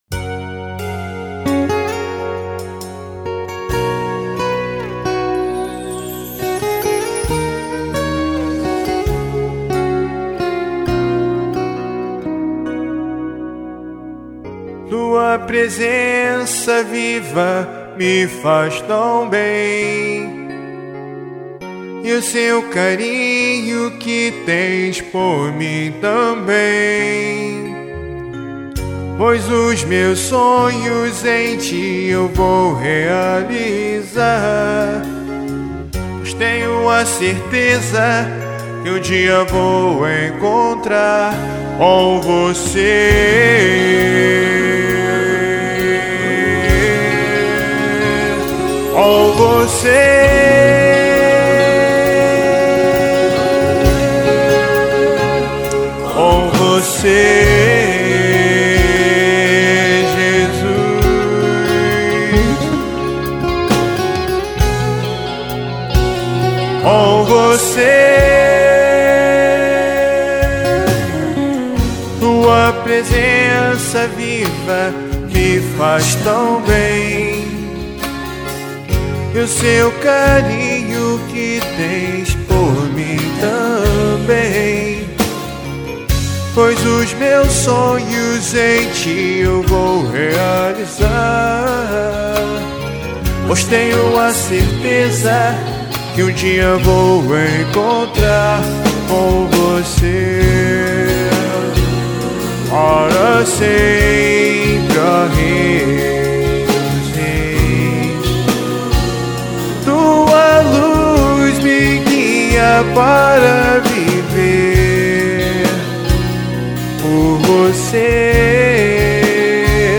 com temática de louvor.
rock cristão underground